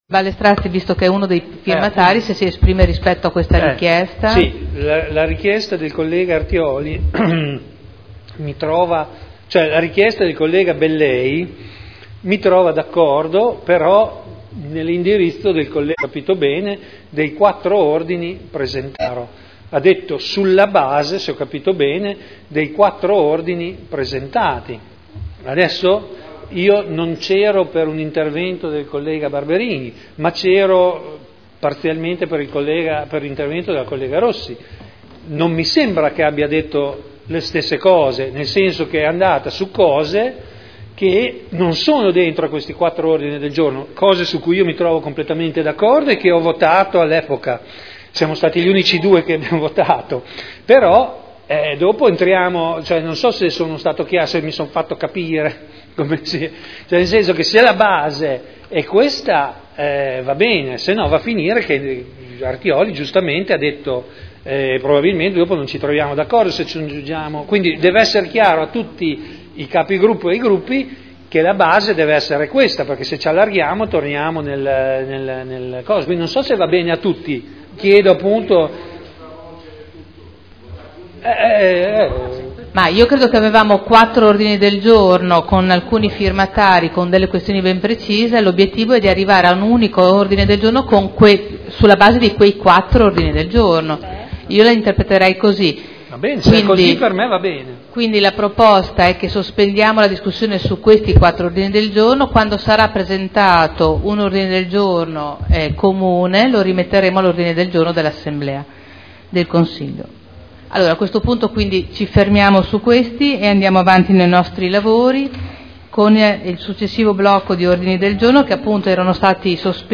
Vittorio Ballestrazzi — Sito Audio Consiglio Comunale
Seduta del 27/06/2011. Discussione sugli ordini del giorno presentati in consiglio ed inerenti le infiltrazioni mafiose a Modena